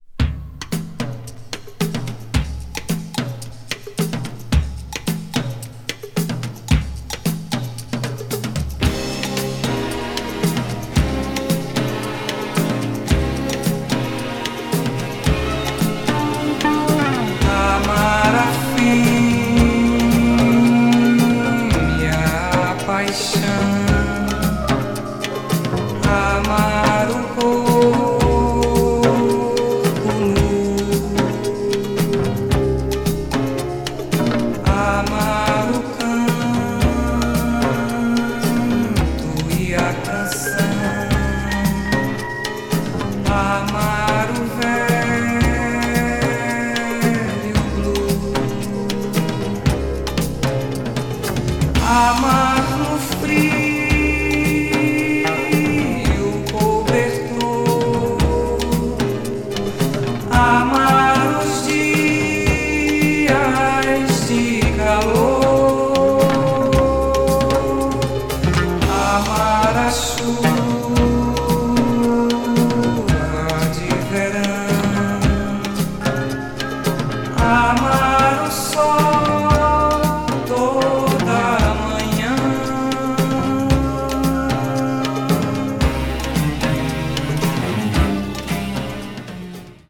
brazil   mellow groove   mpb   world music